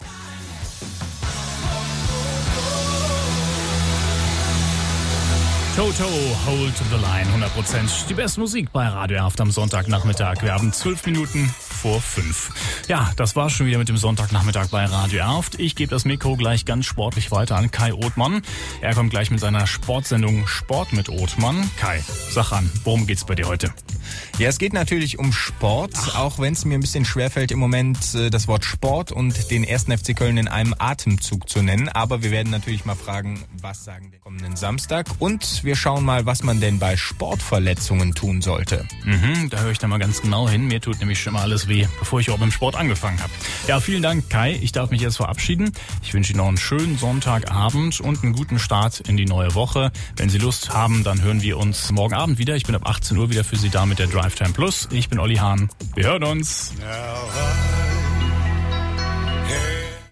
Radio Moderation Demo